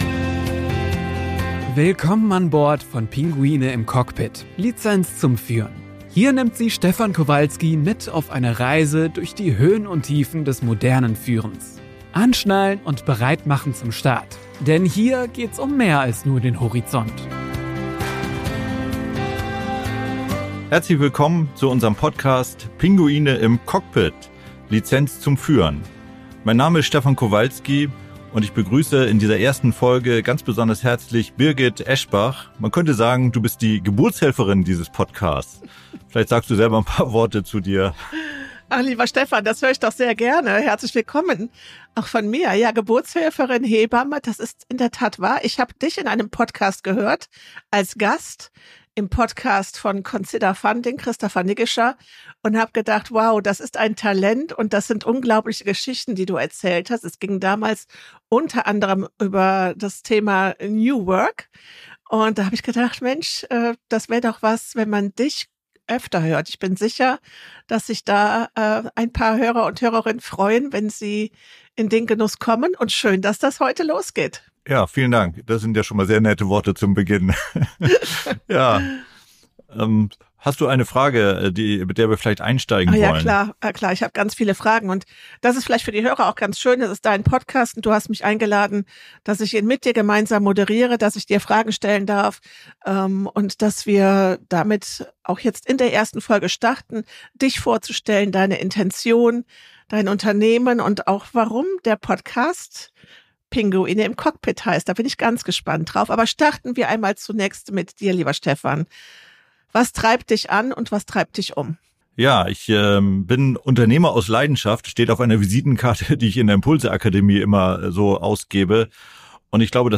Im Gespräch mit Moderatorin